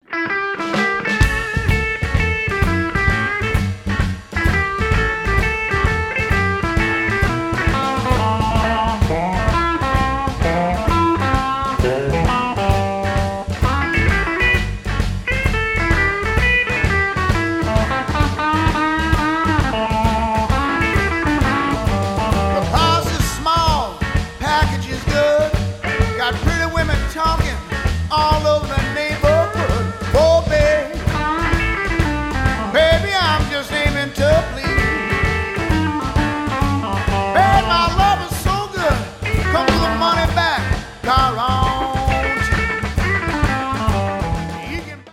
harmonica
Blues